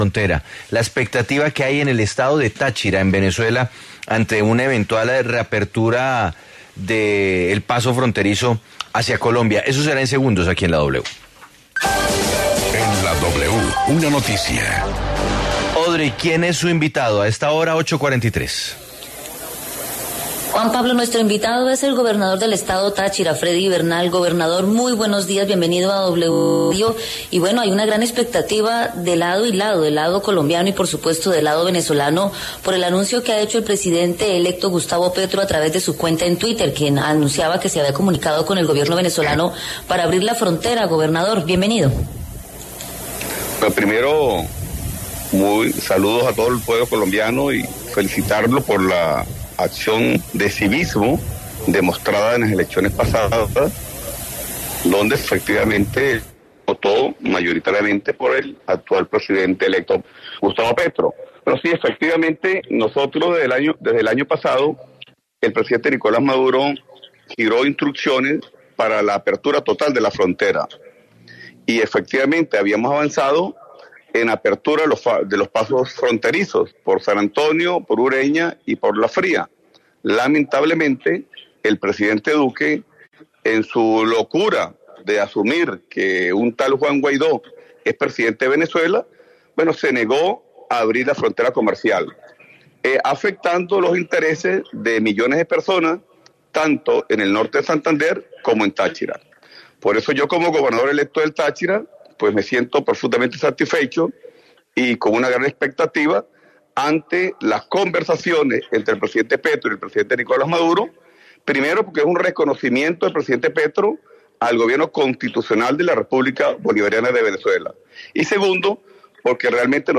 EL Gobernador del estado Táchira Freddy Bernal aseguró en diálogo con La W que, “desde el año pasado el presidente Nicolás Maduro, dio instrucciones para la apertura total de la frontera y efectivamente habíamos avanzado en los pasos fronterizos por San Antonio, Ureña y La Fría, lamentablemente el presidente Duque en su locura de asumir que un tal Juan Guaidó era el presidente de Venezuela se negó a abrir la frontera comercial afectando los intereses de millones de personas tanto en el Norte de Santander como en el estado Táchira, como gobernador electo del Táchira me siento satisfecho y con una gran expectativa ante las conversaciones de presidente Gustavo Petro y presidente Nicolás Maduro”.